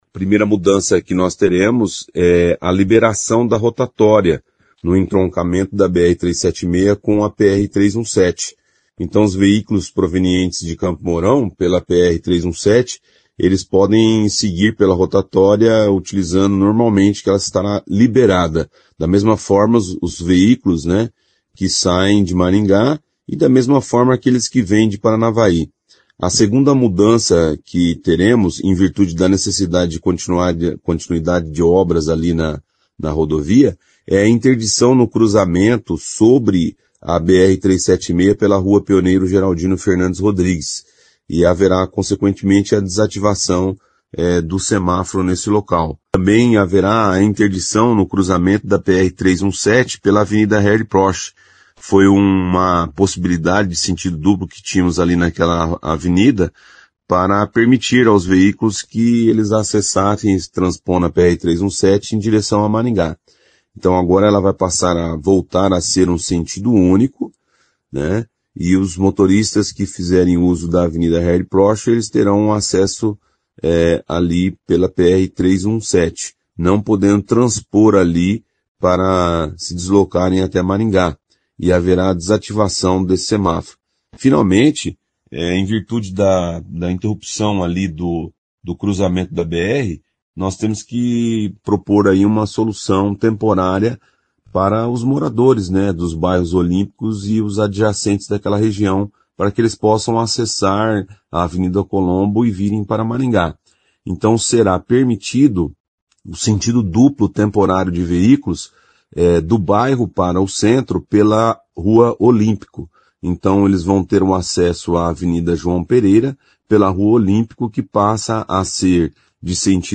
Como parte da mudança no tráfego, também serão interditados o cruzamento da Rua Pioneiro Geraldino Fernandes Rodrigues com a BR-376 e o acesso da Avenida Harry Prochet a essa rodovia; e o cruzamento da Avenida Harry Prochet com a PR-317, como detalha o secretário de mobilidade urbana, Luciano Brito.